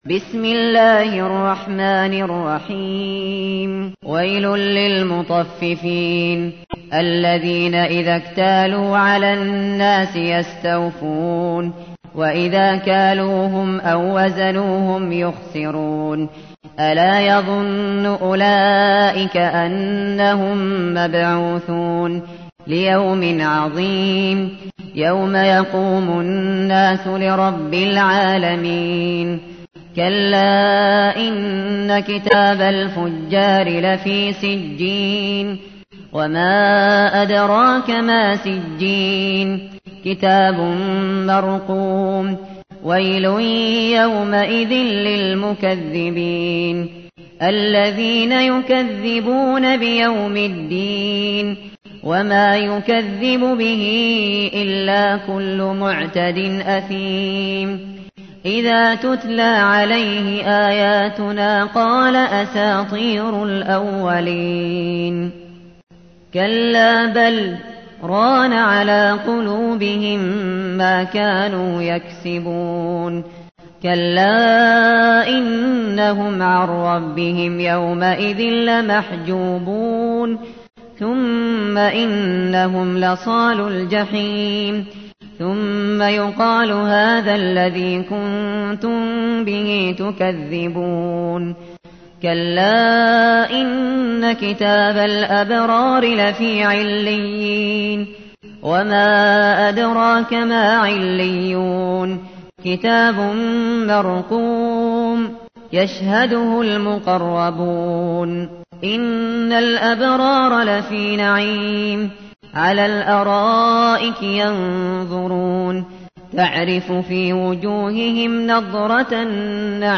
تحميل : 83. سورة المطففين / القارئ الشاطري / القرآن الكريم / موقع يا حسين